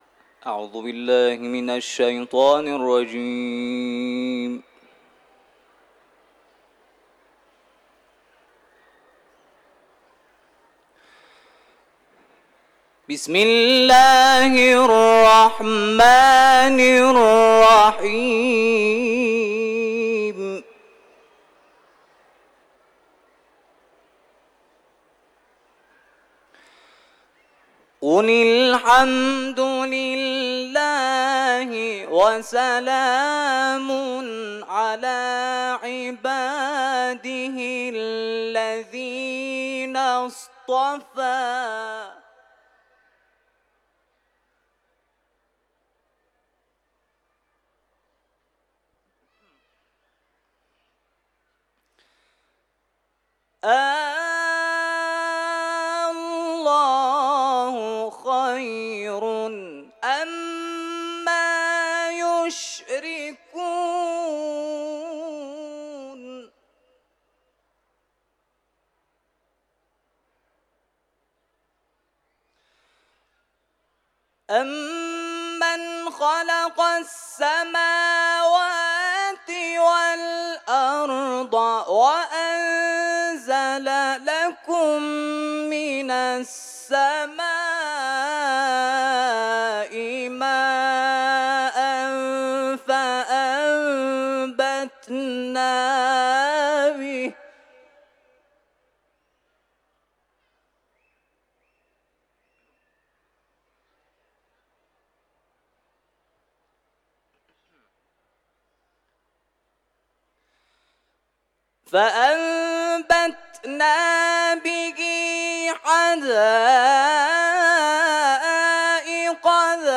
صوت | تلاوت
آیات 59 و 60 سوره «نمل» را در حرم مطهر رضوی تلاوت کرده است که صوت این تلاوت تقدیم مخاطبان ایکنا می‌شود.